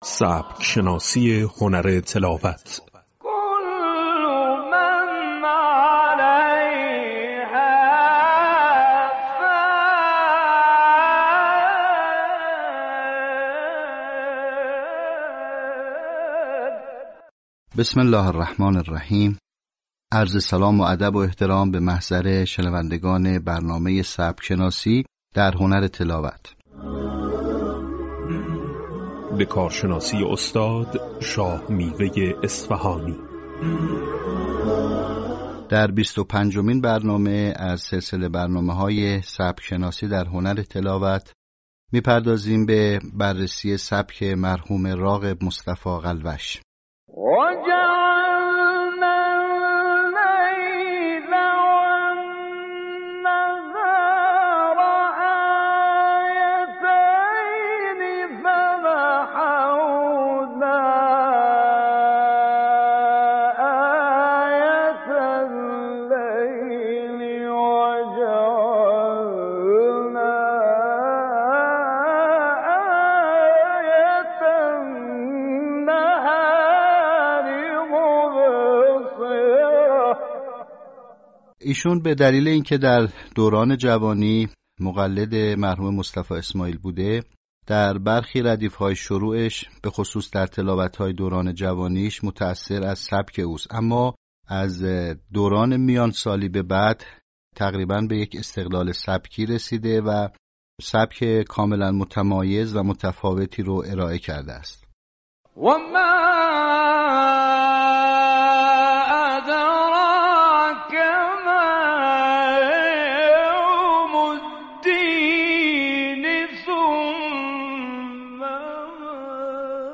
به همین منظور مجموعه آموزشی شنیداری(صوتی) قرآنی را گردآوری و برای علاقه‌مندان بازنشر می‌کند.